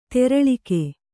♪ teraḷike